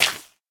Minecraft Version Minecraft Version latest Latest Release | Latest Snapshot latest / assets / minecraft / sounds / block / suspicious_gravel / place3.ogg Compare With Compare With Latest Release | Latest Snapshot